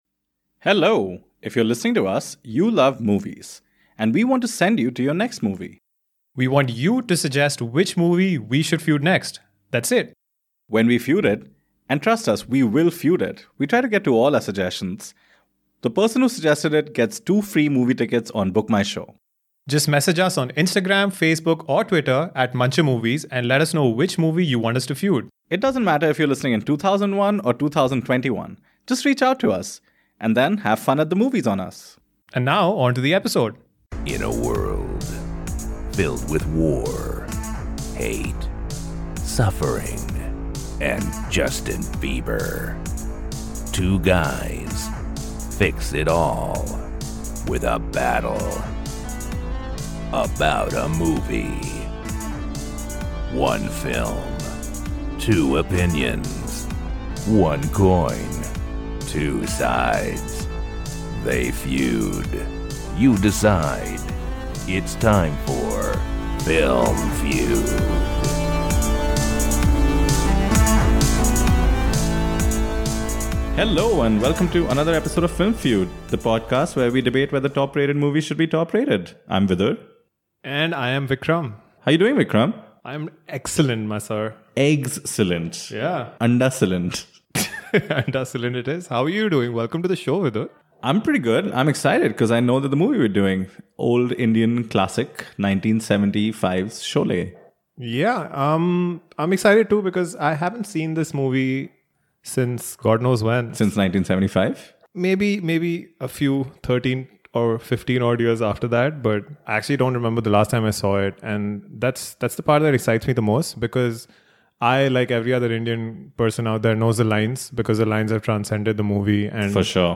And instead of being BFF outlaws who take on a revenge mission in a small village where they both find love, they simply sit in a studio and feud.